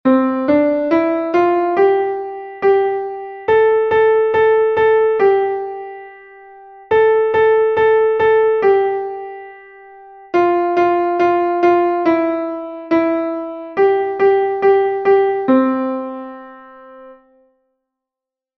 Text und Melodie: Volkslied